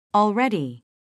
already 미국 [ɔːlrédi] 듣기 1.